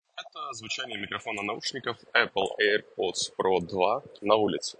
В шумных условиях:
apple-airpods-pro-2-ru-street.mp3